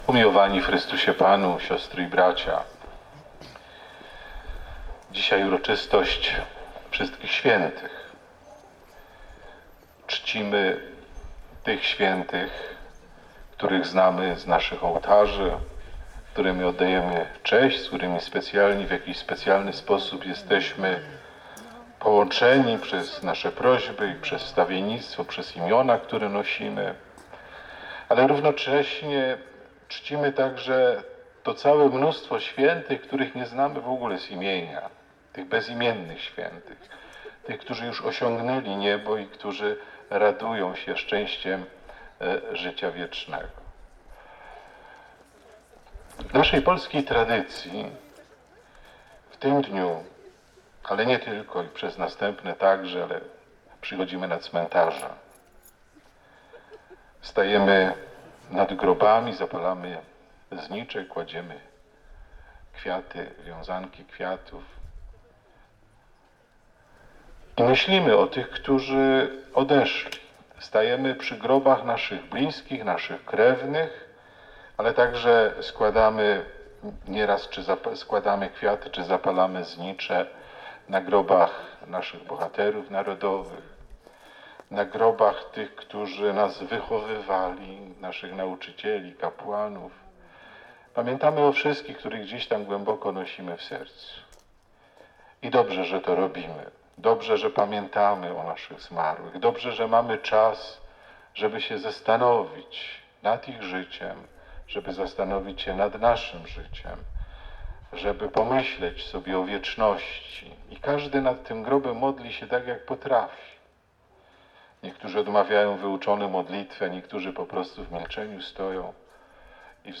Na cmentarzu parafialnym w Siechnicach uroczystą Mszę Świętą odprawił metropolita wrocławski abp Józef Kupny.
Zapraszamy do wsłuchania się w treść kazania, jakie wygłosił ksiądz Arcybiskup.
Homilia-biskupa-Siechnice.mp3